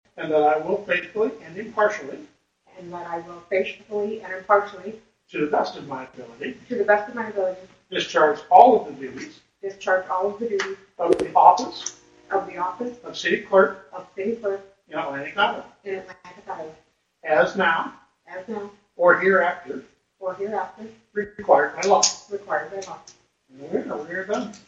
(Atlantic, IA) – Atlantic Mayor Rob Clausen, Wednesday evening (March 11), issued the Oath of Office to Atlantic’s new City Clerk, Jackie Carl.